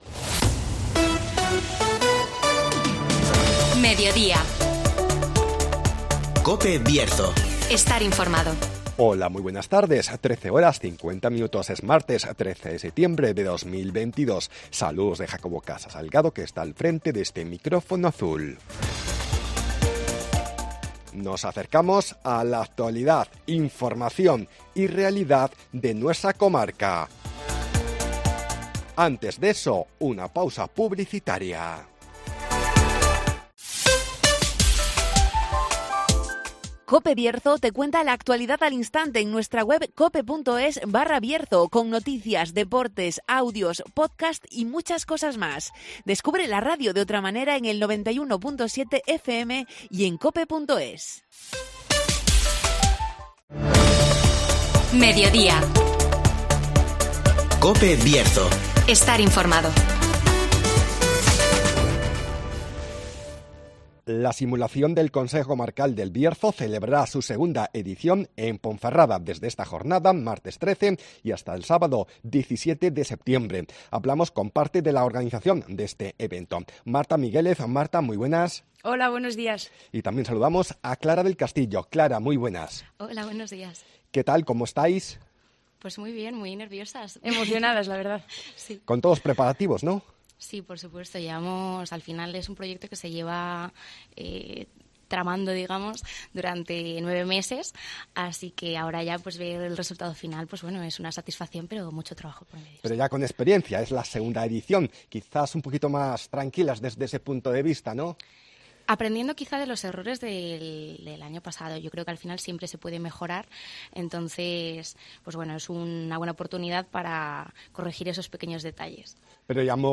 La Simulación del Consejo Comarcal del Bierzo celebrará su segunda edición en Ponferrada (Entrevista